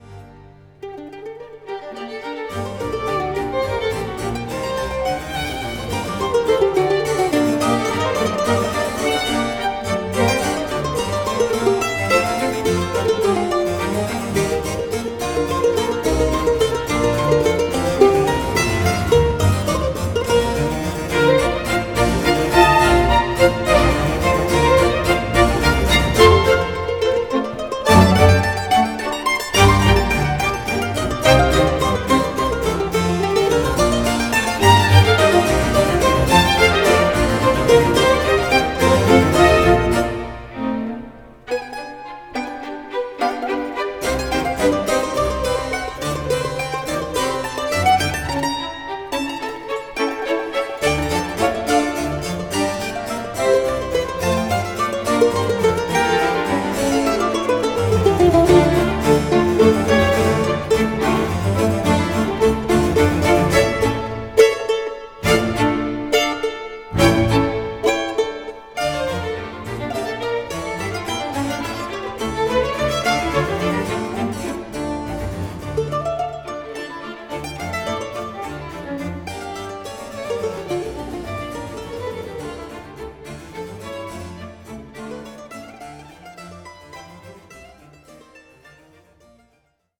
Avi Avital is a musician on a mission, to embed the mandolin strongly in the Western Classical music ethos. If the brilliant effort on his performance (accompanied by Kammerakademie Potsdam) of select Bach compositions on the album titled simply ‘Bach’ is an indicator, he’s well on his way to success.